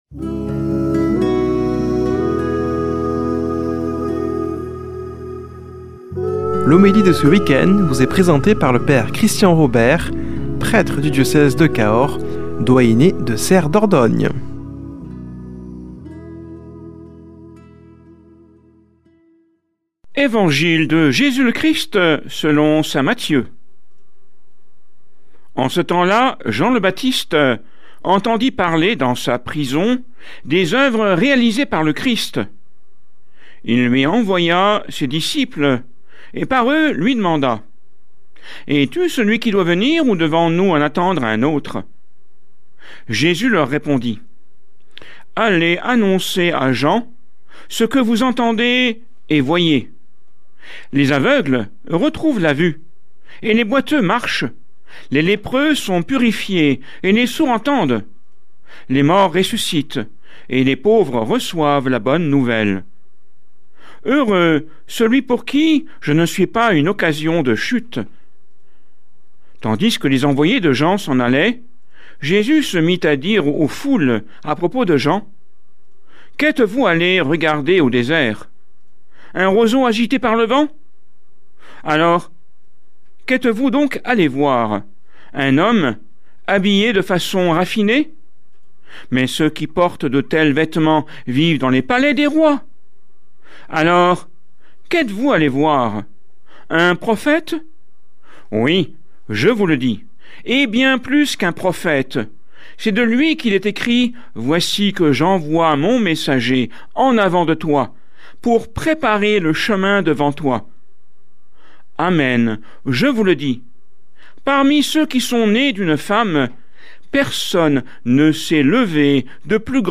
Homélie du 13 déc.